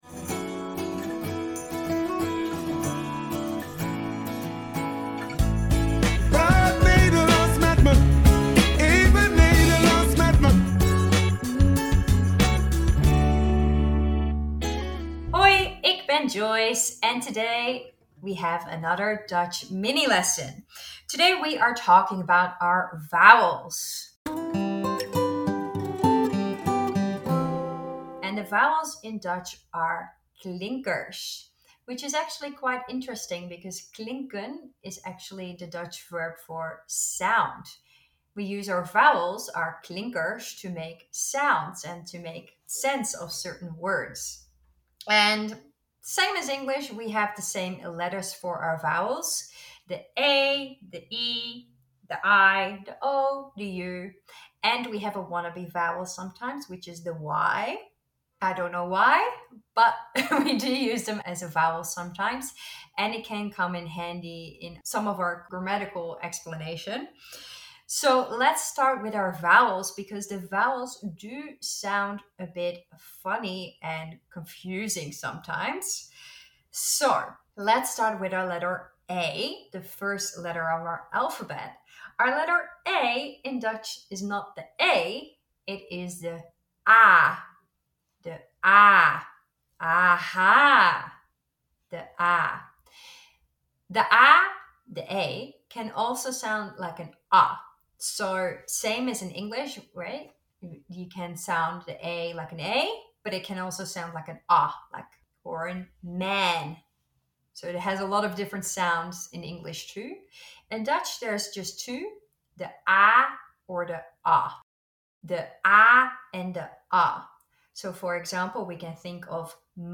The Dutch language has the same vowels as English (A, E, I, O, U) and a “wannabe vowel” (Y). The Dutch vowels sound different than in English.